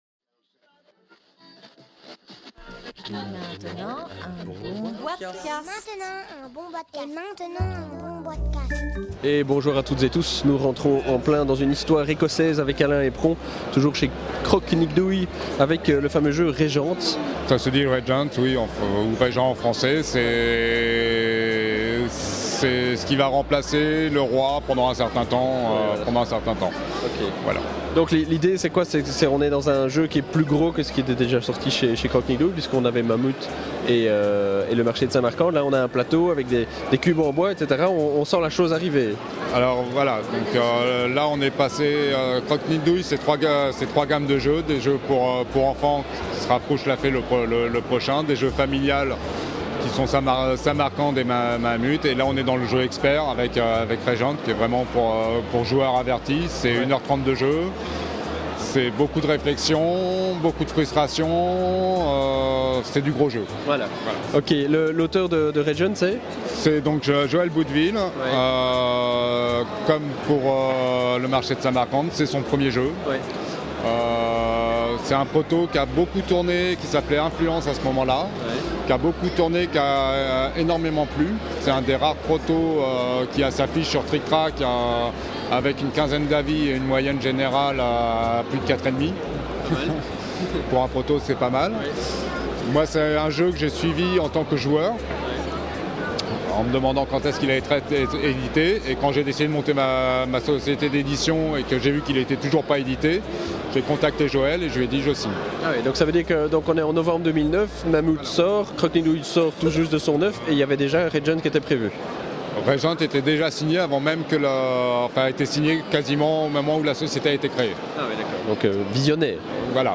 (enregistré au Salon international du Jeu de Société de Essen – Octobre 2010)